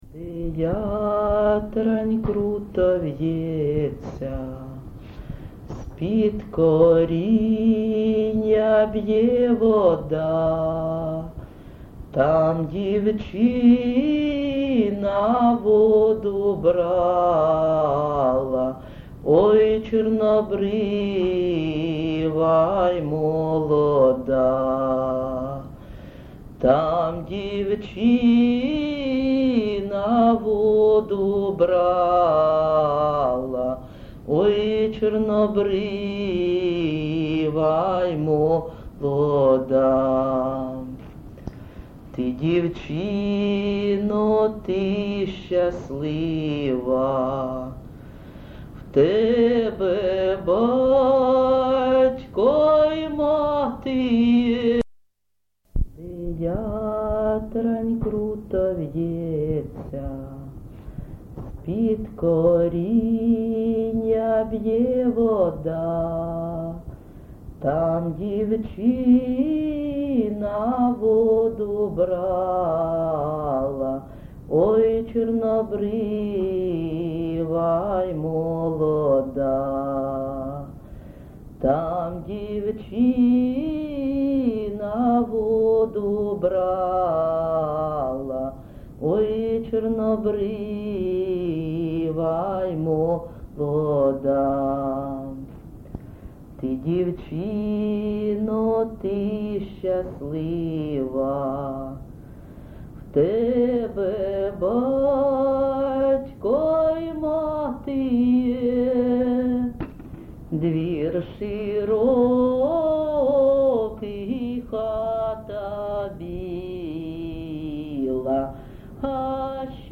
ЖанрКозацькі, Пісні літературного походження
Місце записус-ще Олексієво-Дружківка, Краматорський район, Донецька обл., Україна, Слобожанщина